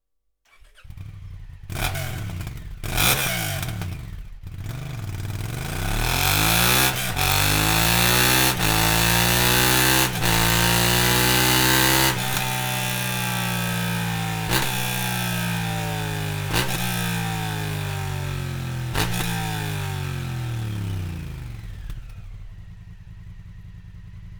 Sound Serienauspuff